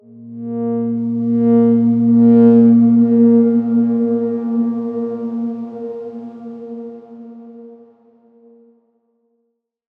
X_Darkswarm-A#2-pp.wav